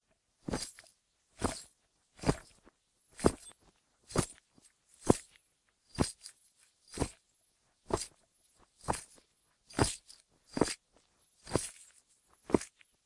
Звуки дикого запада
Шаги ковбоя в звонких шпорах